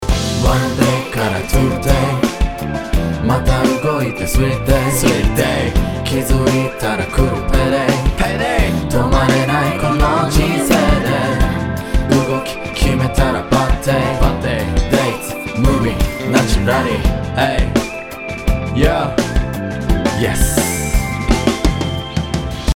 サイドに広げたコーラスと真ん中に鎮座するメインボーカル
ボーカルの場合、メインかそれ以外かで分けて考えたいと思います（今回はボーカルっていうかラップだけど）。
ちなみにこのEQをオフにするとこんな感じです。